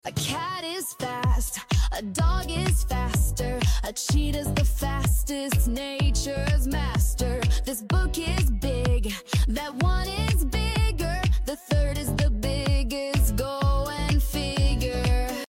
🎶 From big, bigger, biggest to good, better, best, this fun grammar song will help you master how to compare things in English while singing along!
Fun rhythm keeps you motivated